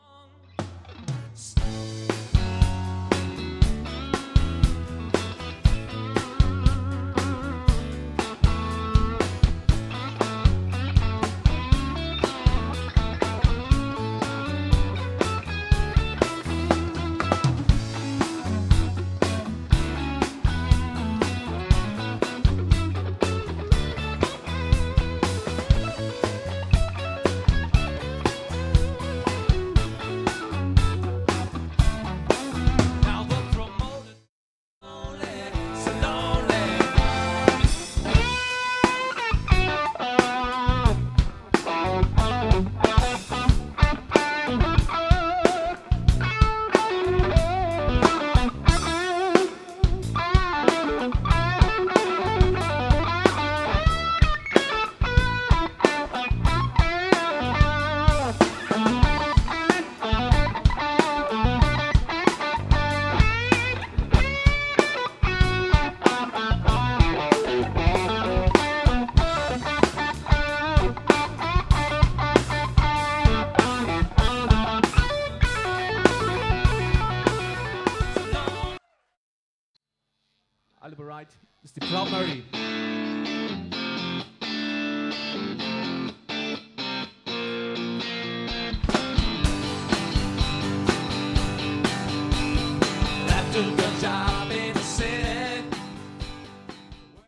Hier kannst du erst Hals (Häussel Classic A2), dann Steg (Häussel BigMag) und dann die Mittelstellung kurz hören.
haeussel_strat.mp3